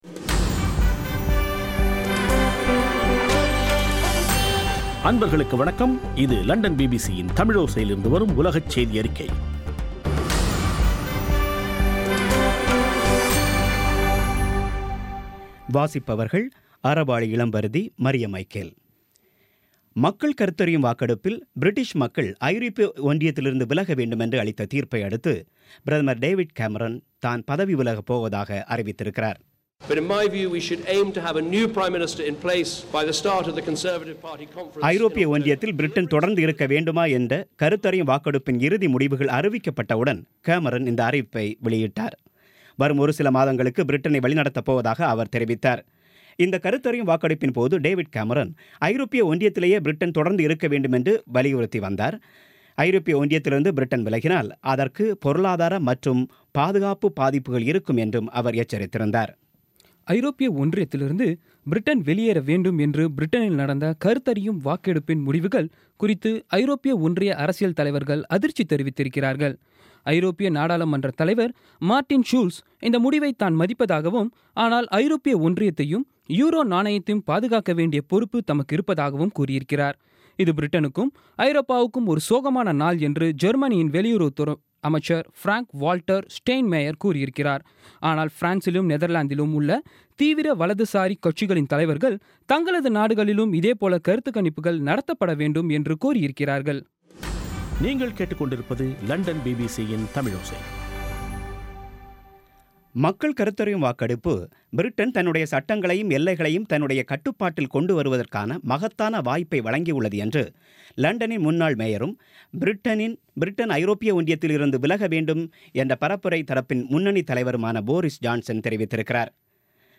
பிபிசி தமிழோசை செய்தியறிக்கை (24.06.16)